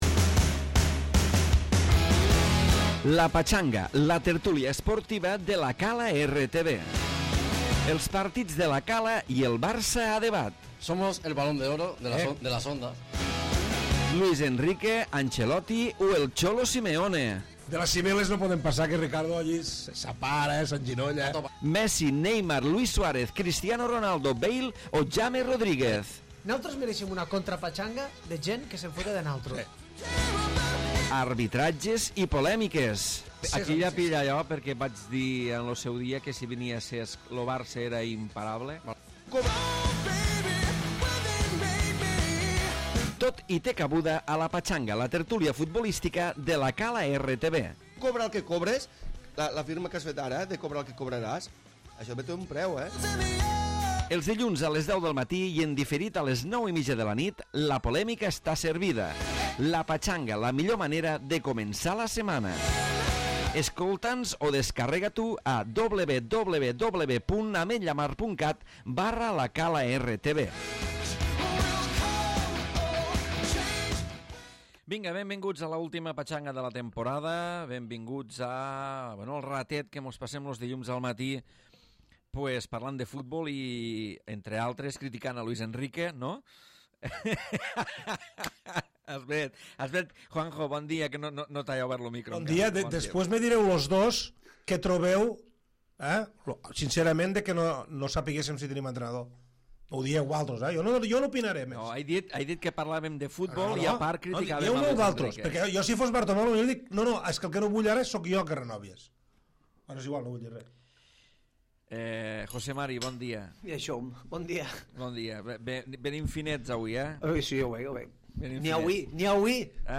Darrera tertúlia esportiva de la temporada centrada en la final de la Champions i la celebració del Triplet.